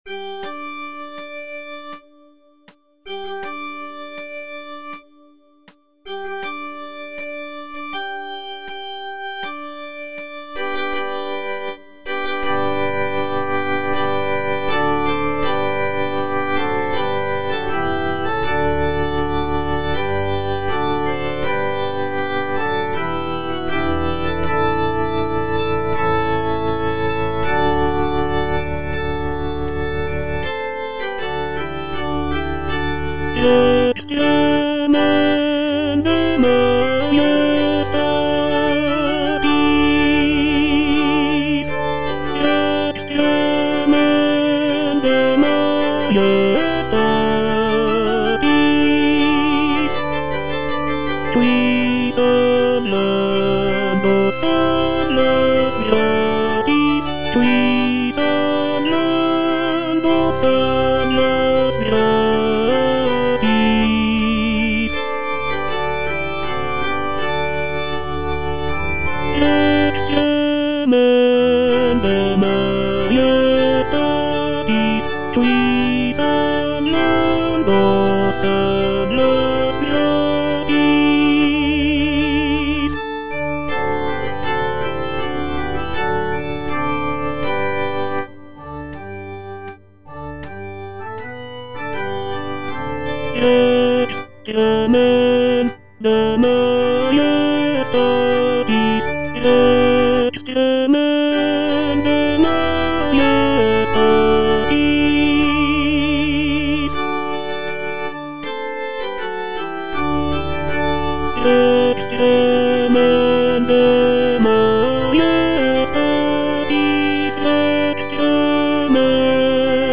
(tenor I) (Voice with metronome) Ads stop
is a sacred choral work rooted in his Christian faith.